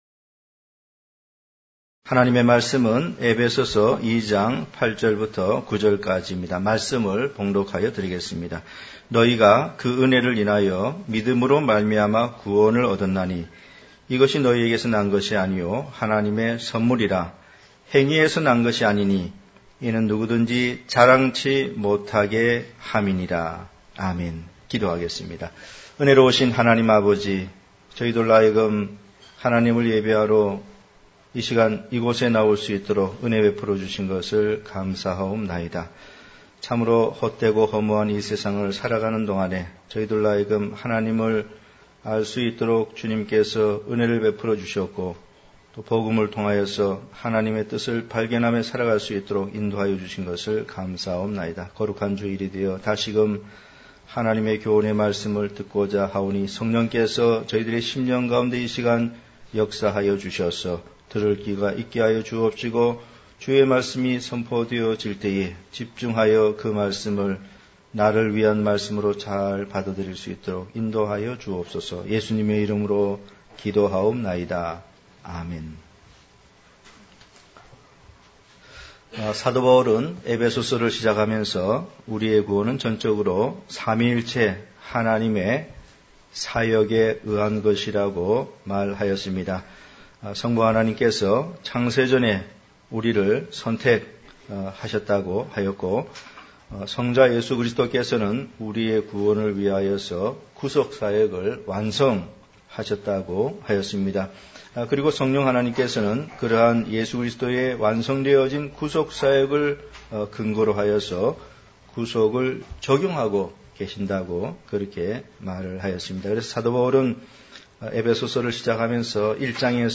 은혜를 인하여 믿음으로 말미암아 > 단편설교 | 진리교회